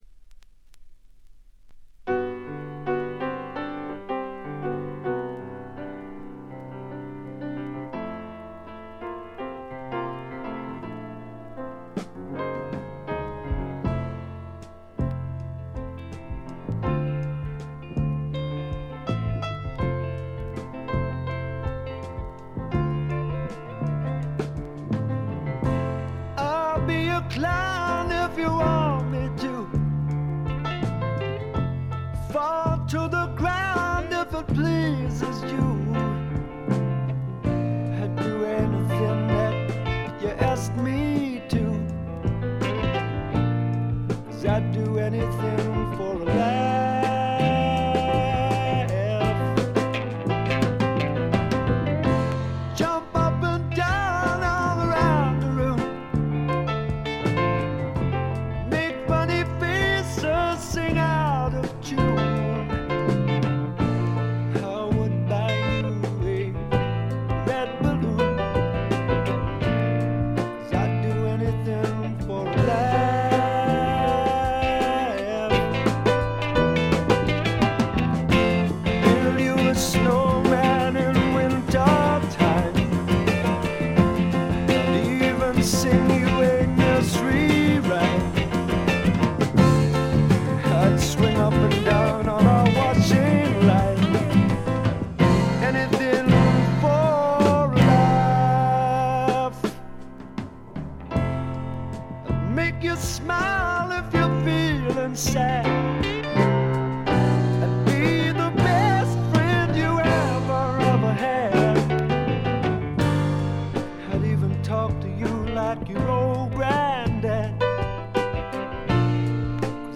ちょいと鼻にかかった味わい深いヴォーカルがまた最高です。
試聴曲は現品からの取り込み音源です。